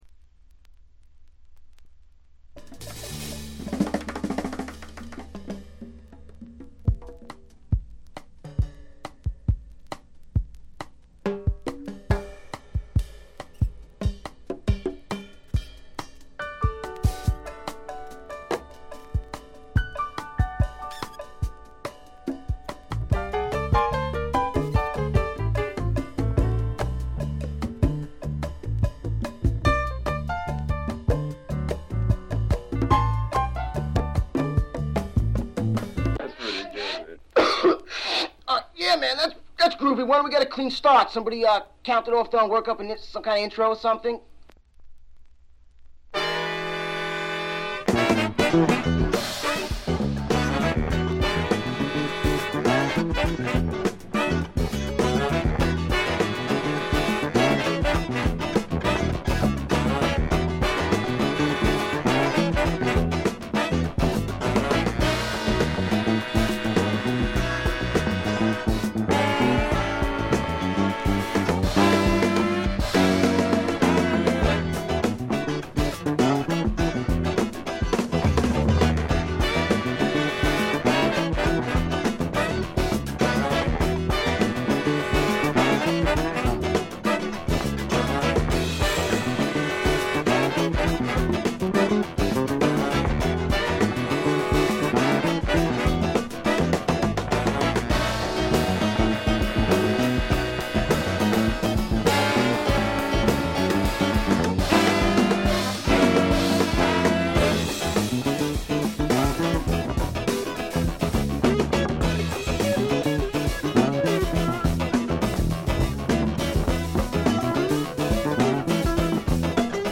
よりファンキーに、よりダーティーにきめていて文句無し！
試聴曲は現品からの取り込み音源です。